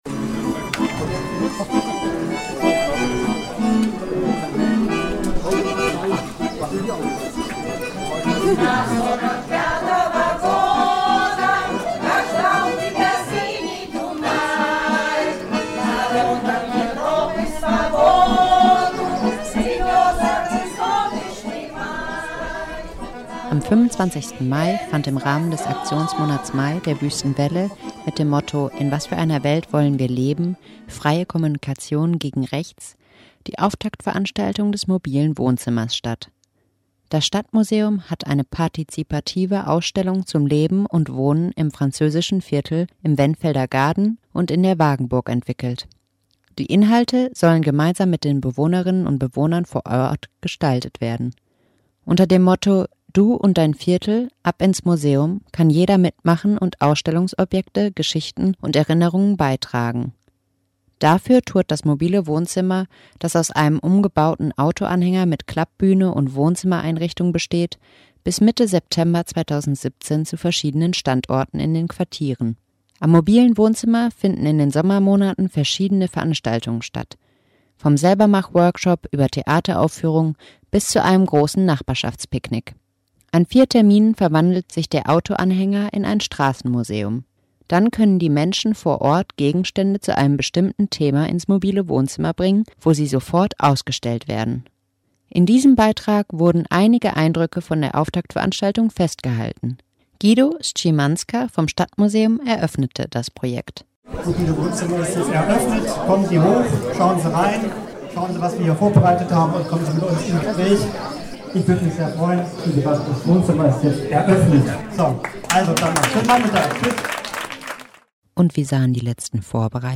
In diesem Beitrag wurden einige Eindrücke von der Auftaktveranstaltung festgehalten.